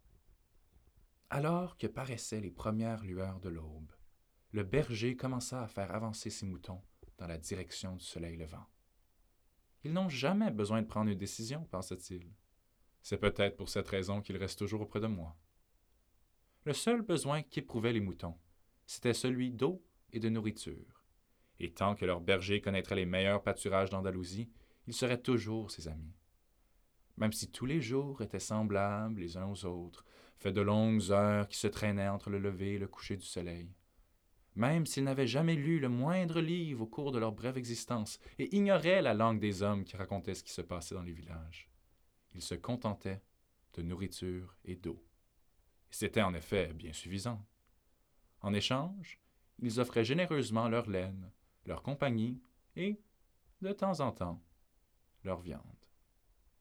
Narration - FR